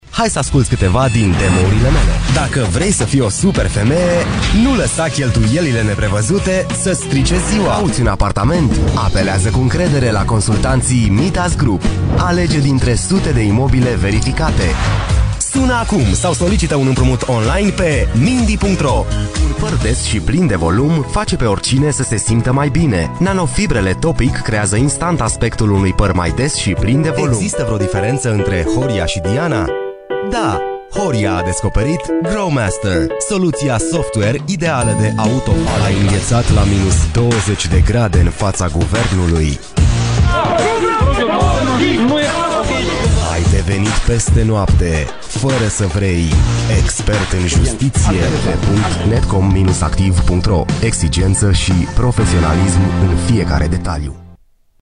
男罗05 罗马尼亚语男声 干音 沉稳|科技感|积极向上|时尚活力|素人
男罗05 罗马尼亚语男声 游戏机产品介绍MG动画趣味快语速 沉稳|科技感|积极向上|时尚活力|素人